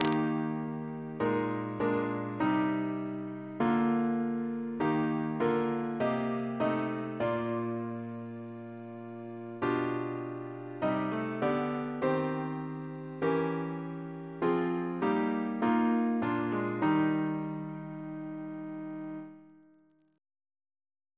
CCP: Chant sampler
Double chant in E♭ Composer: Edwin Edwards (1830-1907) Reference psalters: PP/SNCB: 29